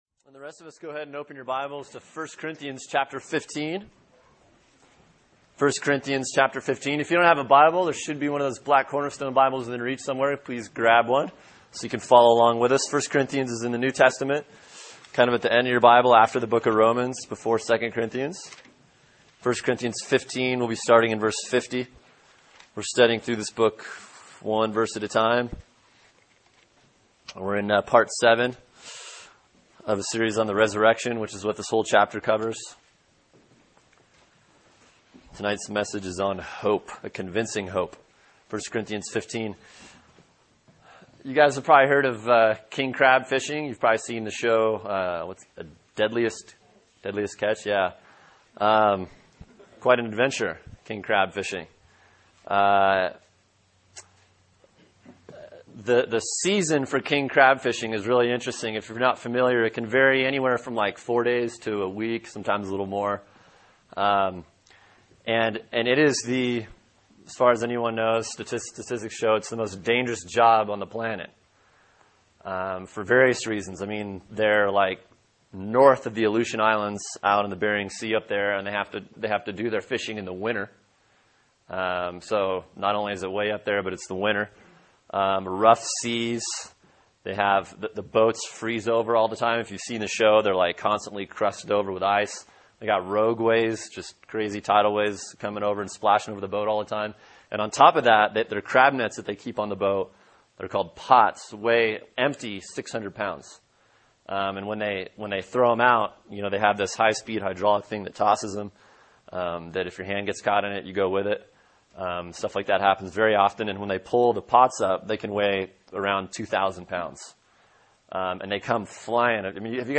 Sermon: 1 Corinthians 15:50-58 “Resurrection, part 7” | Cornerstone Church - Jackson Hole